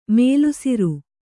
♪ mēlusiru